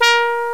Index of /m8-backup/M8/Samples/FAIRLIGHT CMI IIX/BRASS2